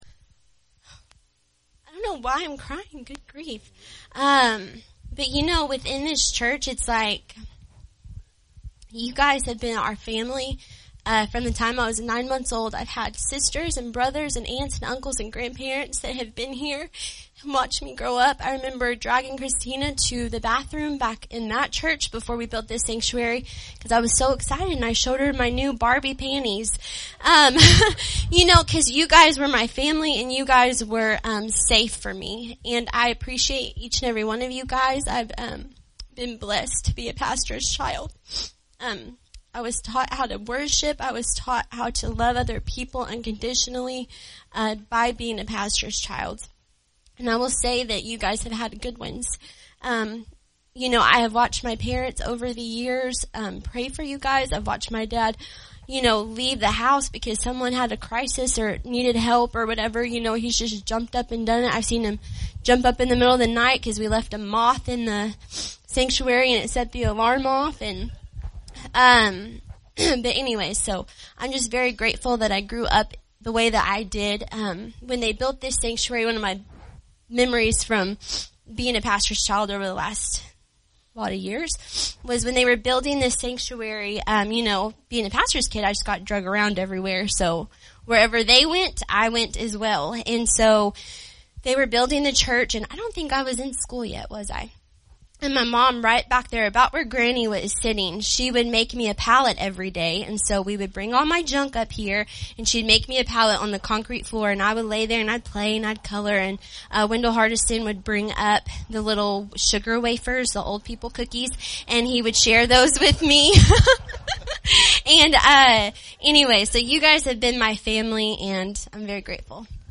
Pastor’s Retirement Service